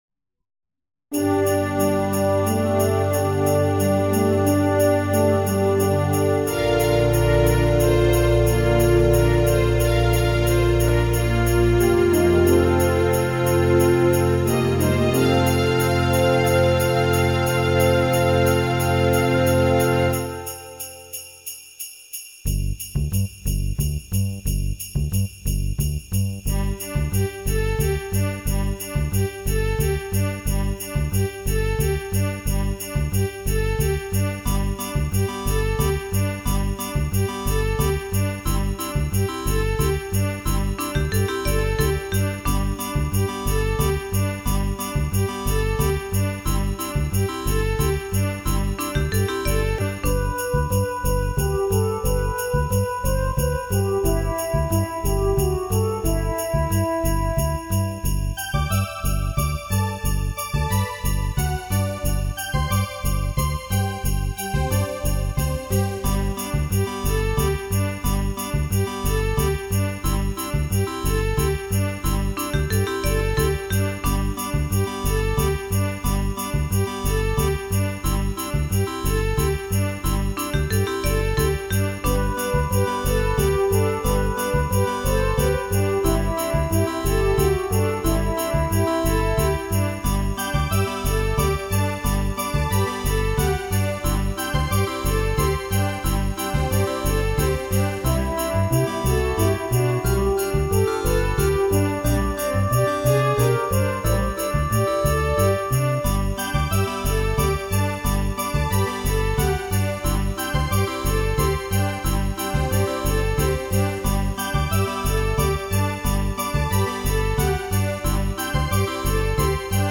XG音源MU500を使用しています。
このページのデータは、低音は原音と同じ程度に抑えてありますので
○打ち込みMIDI
ベタ打ちでは出てこない空間を是非一度どうぞ！